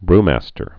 (brmăstər)